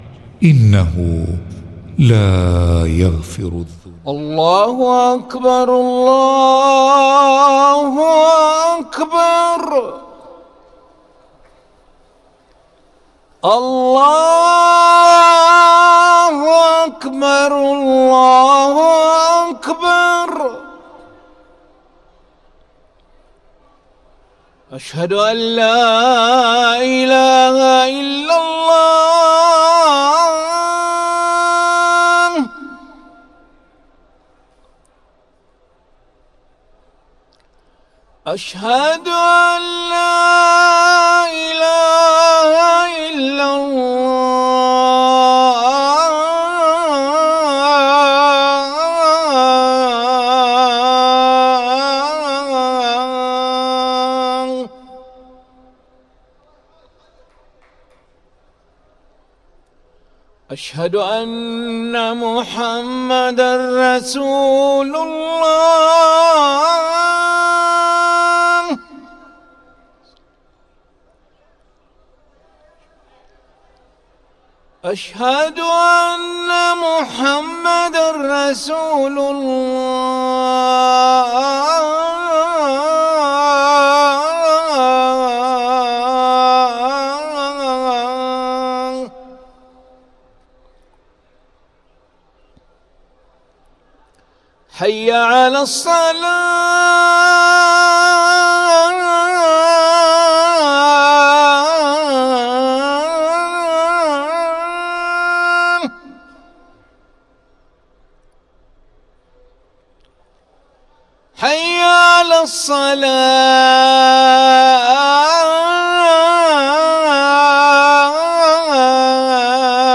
أذان العشاء للمؤذن علي ملا الخميس 11 ذو الحجة 1444هـ > ١٤٤٤ 🕋 > ركن الأذان 🕋 > المزيد - تلاوات الحرمين